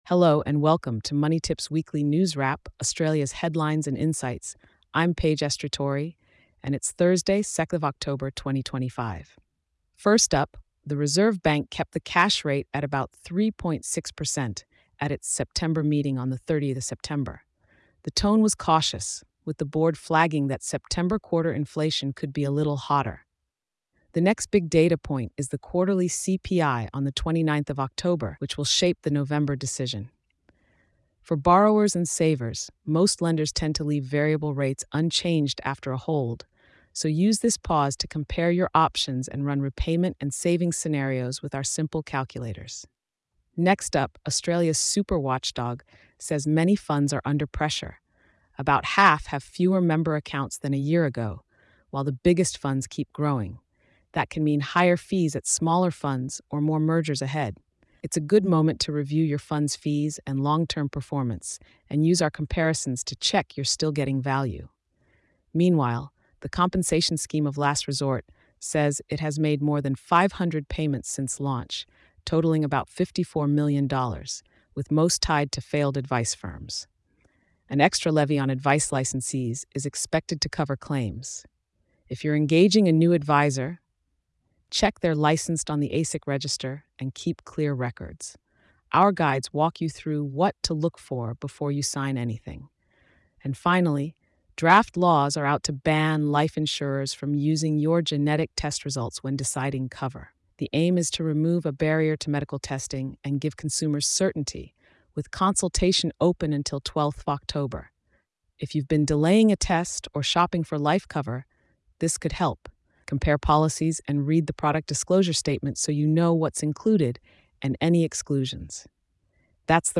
Designed to help you cut through noise and stay informed, it’s a trustworthy weekly brief you can count on, delivered with a calm, approachable tone.